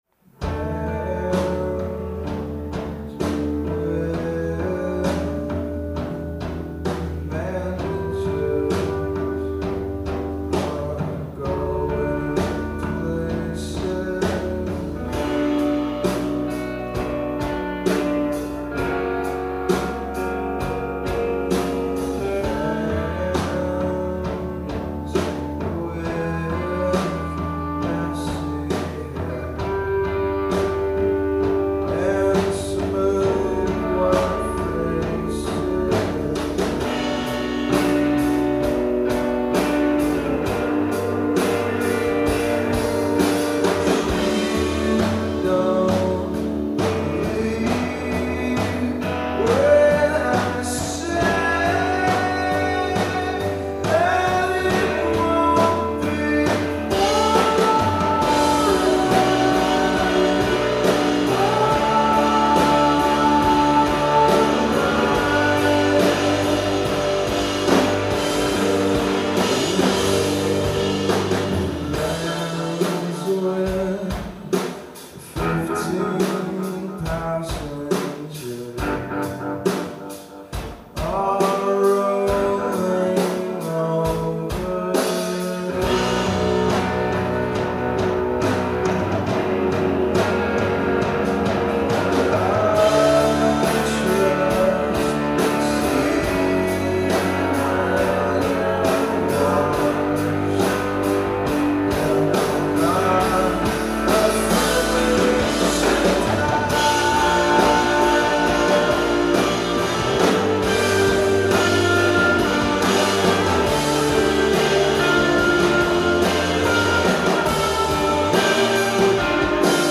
Live at the Somerville Theater, Somerville, MA